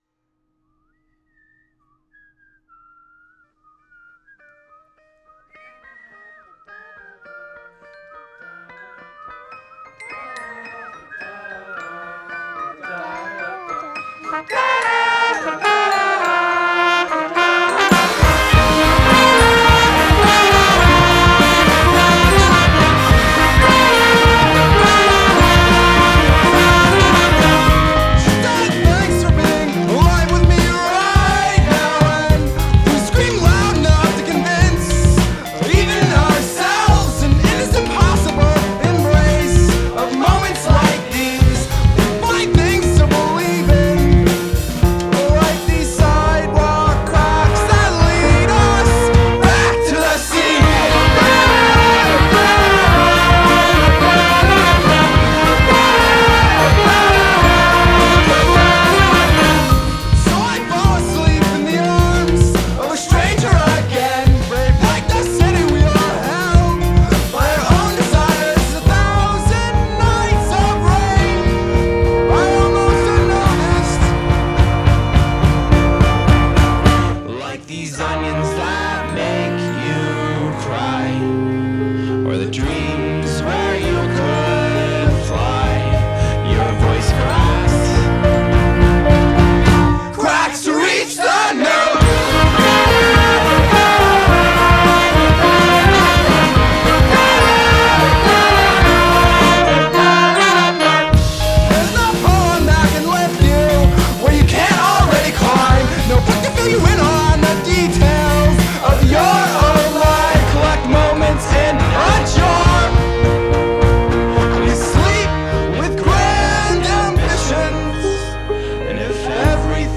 pop-punk
Une musique mélodique, saturée, souriante, parfois cuivrée.